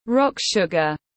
Đường phèn tiếng anh gọi là rock sugar, phiên âm tiếng anh đọc là /rɒk ˌʃʊɡ.ər/
Rock sugar /rɒk ˌʃʊɡ.ər/